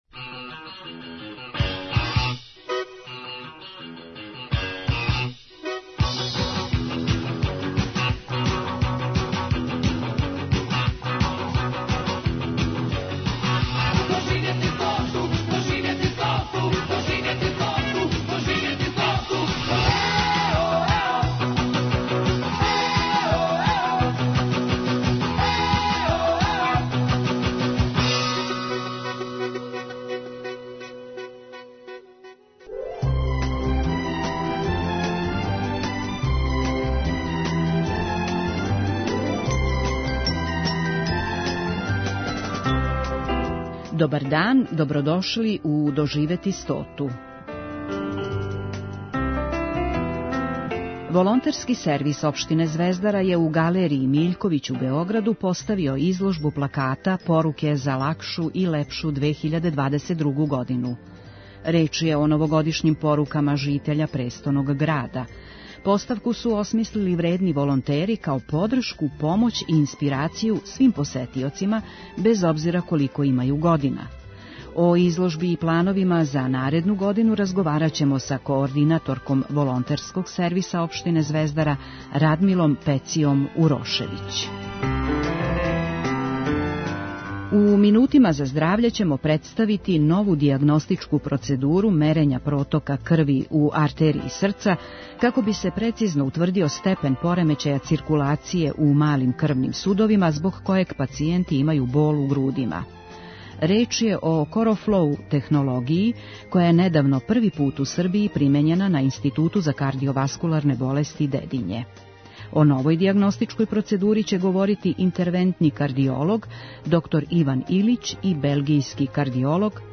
доноси интервјуе и репортаже посвећене старијој популацији